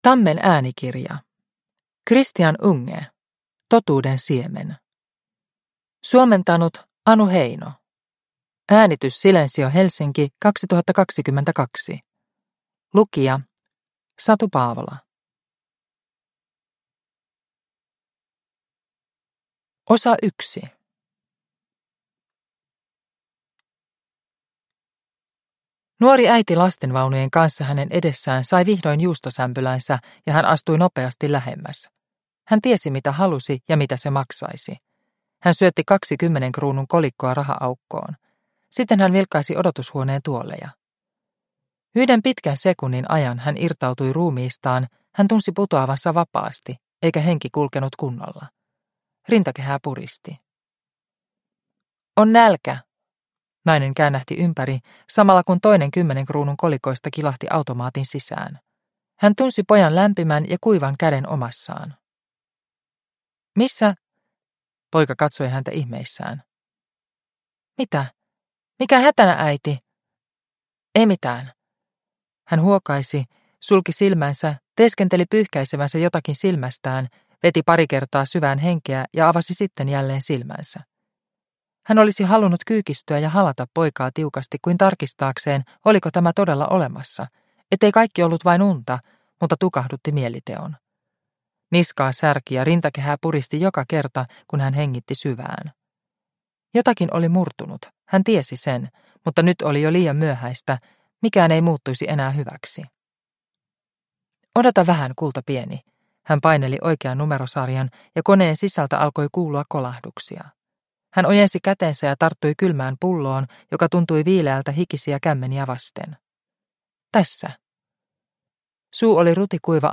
Totuuden siemen – Ljudbok – Laddas ner